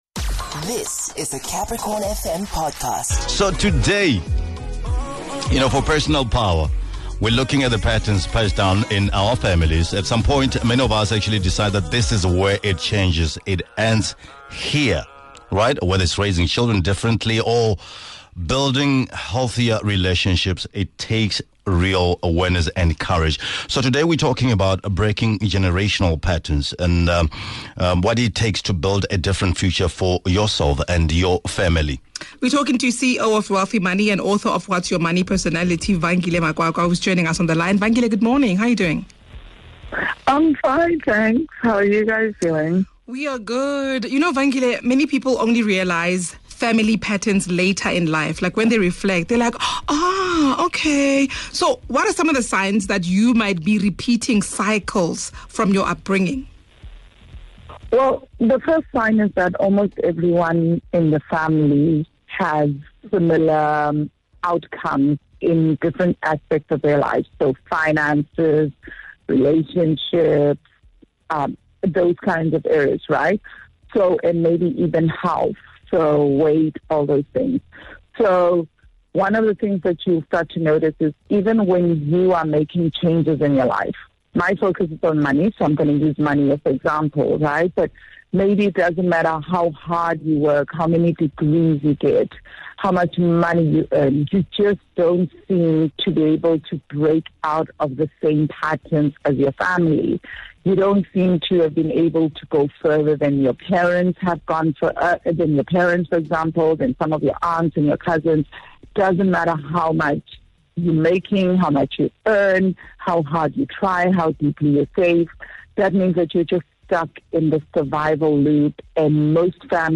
This is a powerful conversation about growth, healing, and creating a different future.